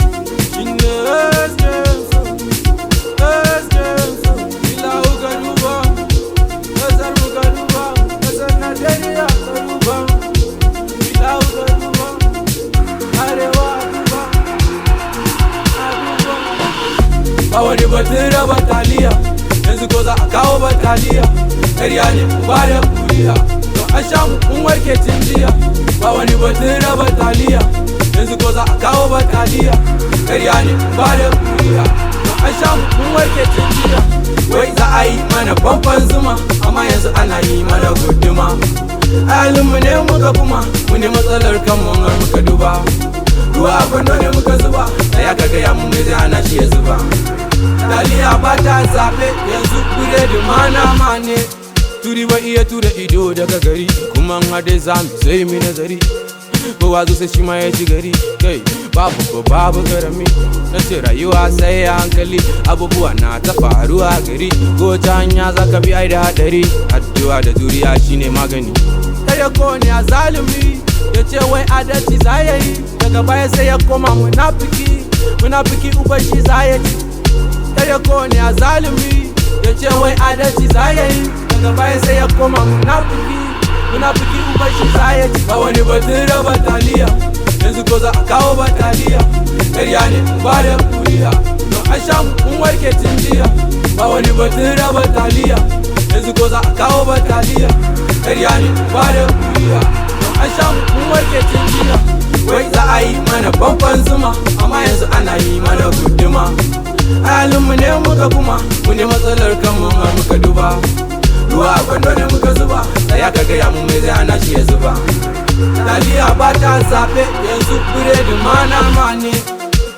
Hausa Music
an Arewa rooted song